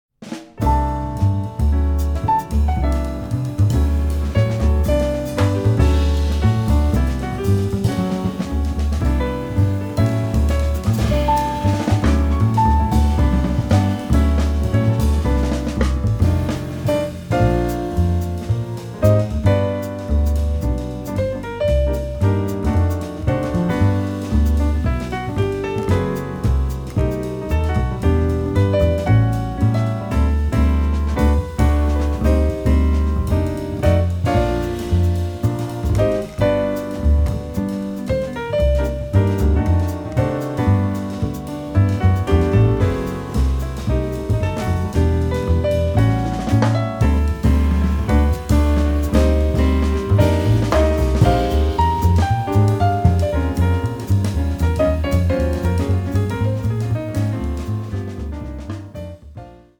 輝き、駆け巡るピアノ。
ミディアム・ファーストでたたみ掛けるようにスイングするピアノが圧巻。